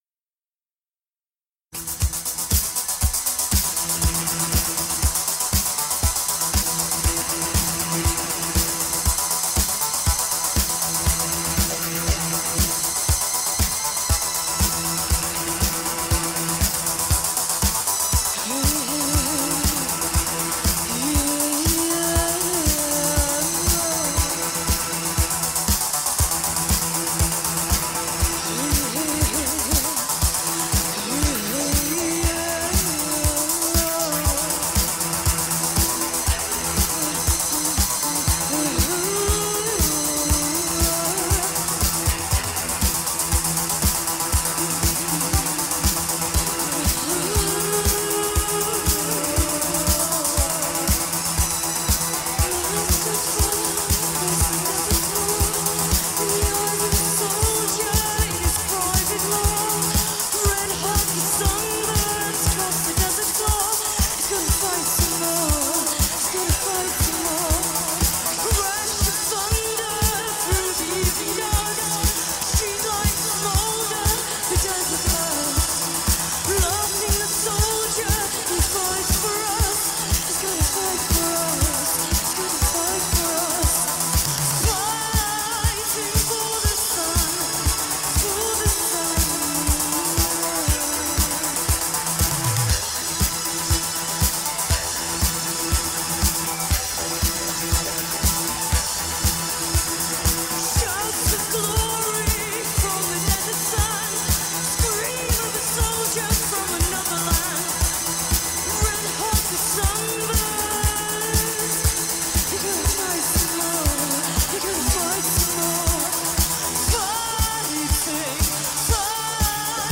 recorded live at The Paris Theatre, London
twisting Synth-Pop into knots